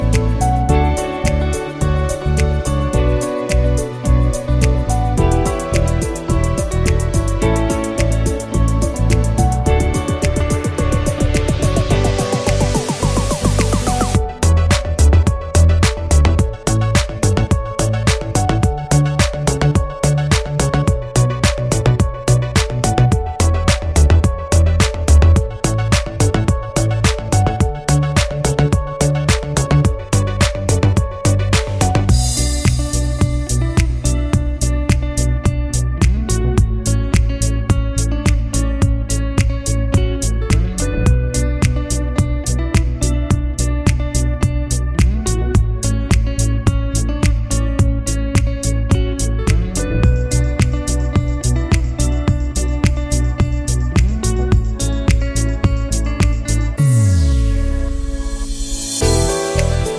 No Backing Vocals and No sample Pop (2010s) 3:32 Buy £1.50